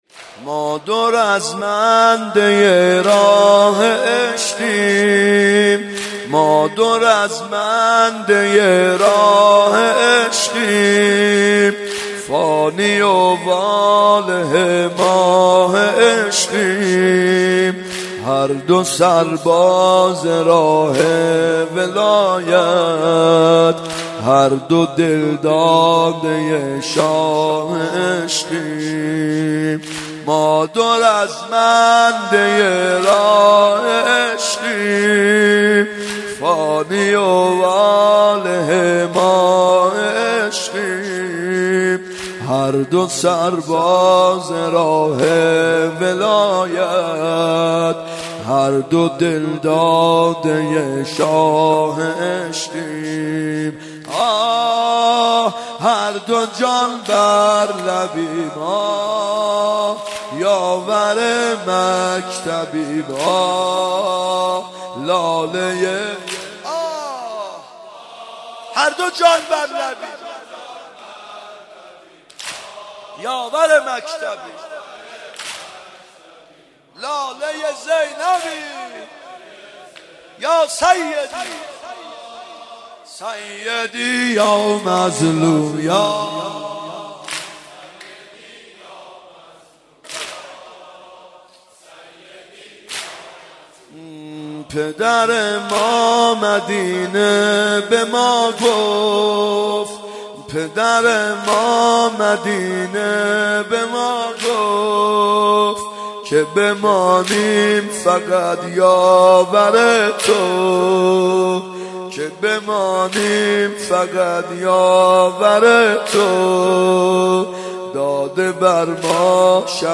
محرم 92 شب چهارم واحذ (ما دو رزمنده راه عشقیم
محرم 92 ( هیأت یامهدی عج)